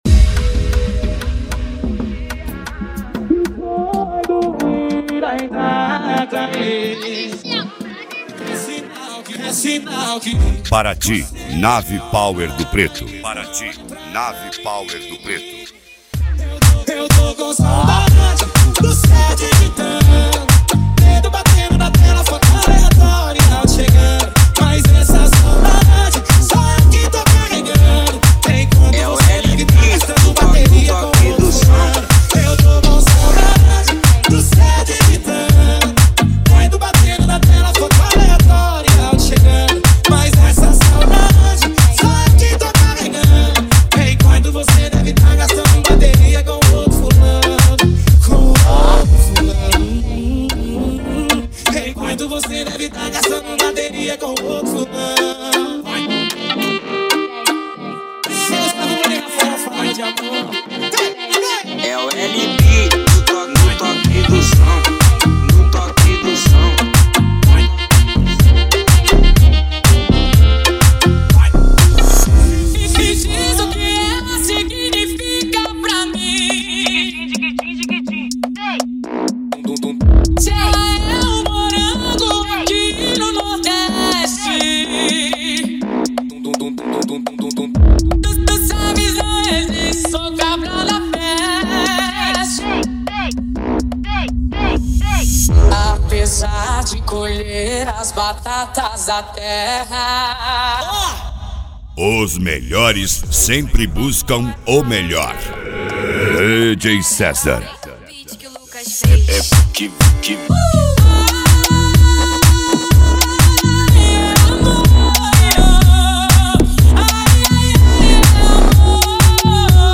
Mega Funk
SERTANEJO